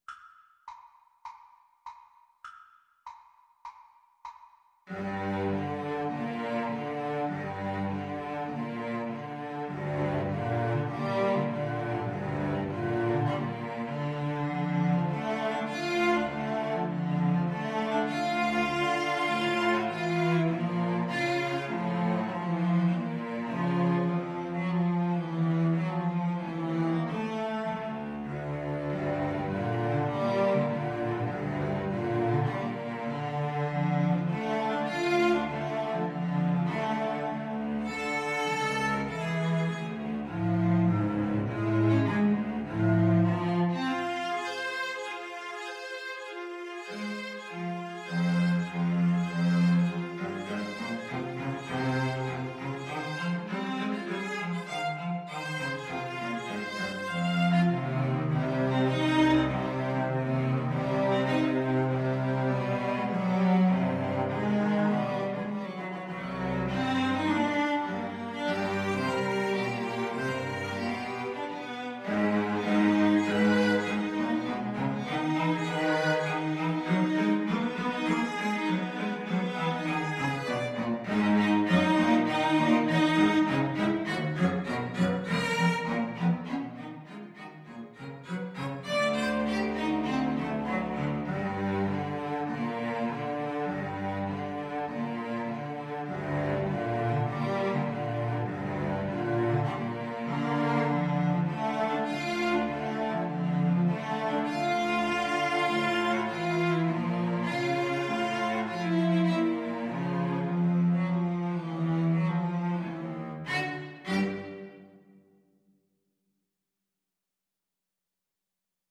Cello Trio  (View more Advanced Cello Trio Music)
Classical (View more Classical Cello Trio Music)